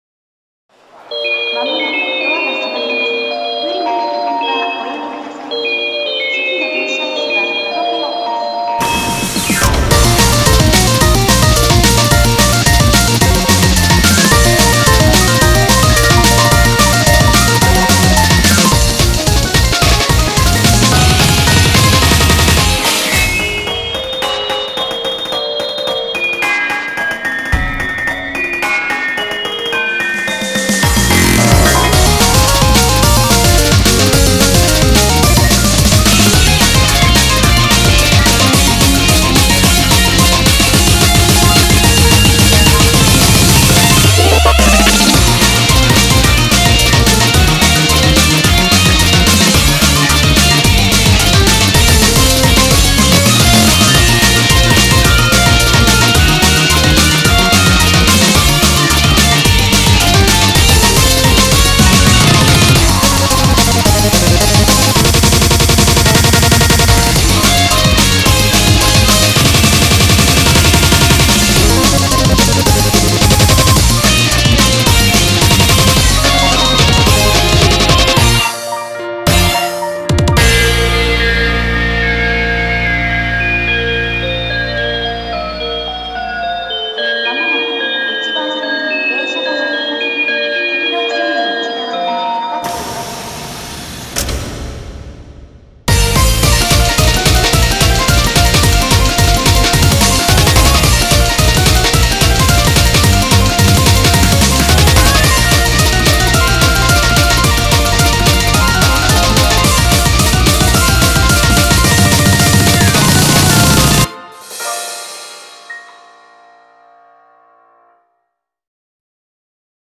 BPM109-218
Audio QualityPerfect (High Quality)